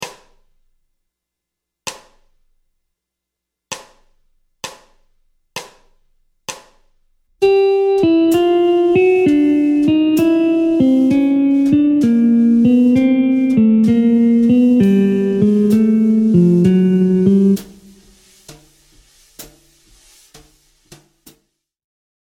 Troisième des positions de gamme, choisie pour un jeu ‘Bop’ sur les gammes usuelles.
Descente de gamme
Gamme-bop-desc-Pos-42-G-Maj.mp3